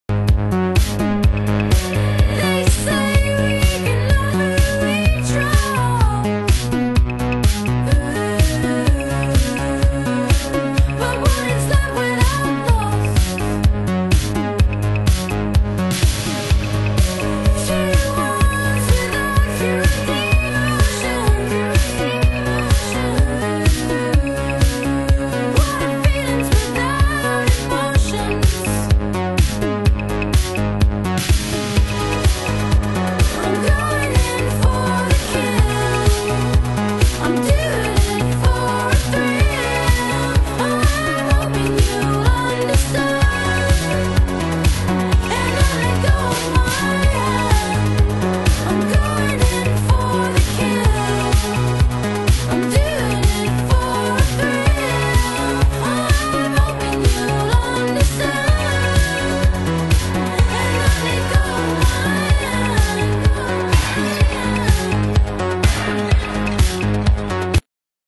DISCO
NEWWAVE